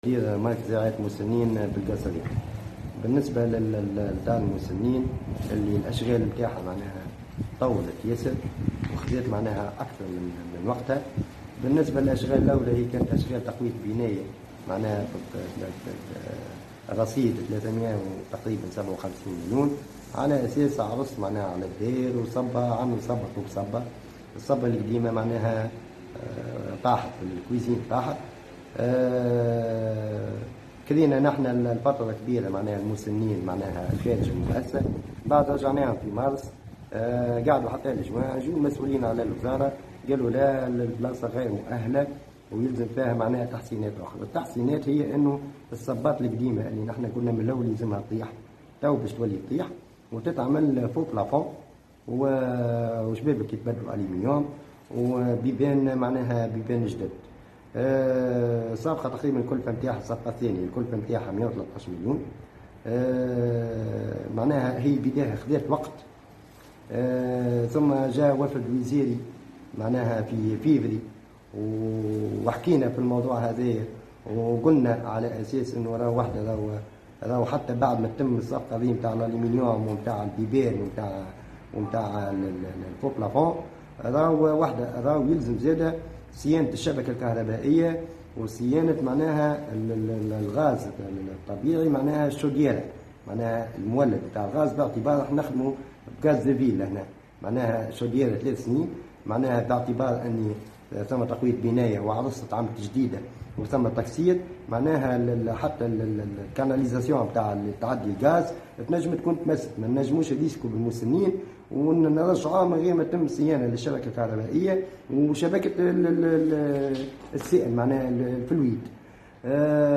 تونس: تأخر في استكمال الأشغال بدار المسنين بالقصرين(صور وتصريح)